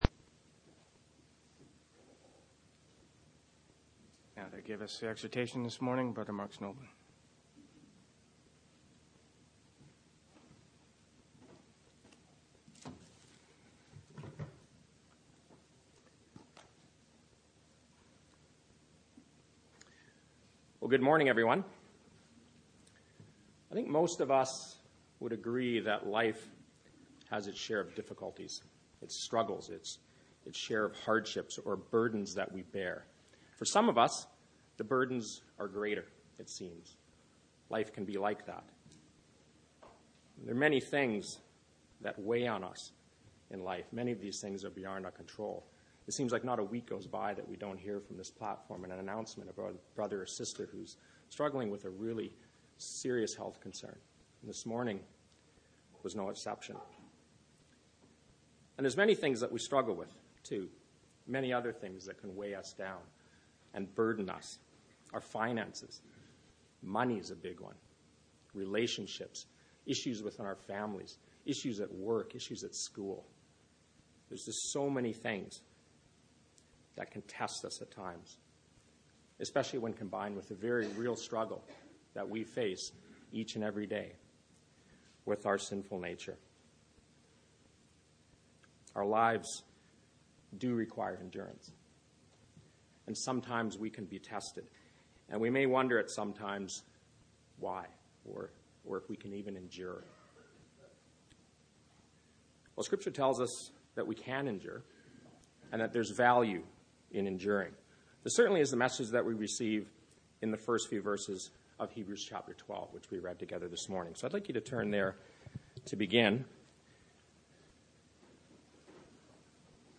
Exhortations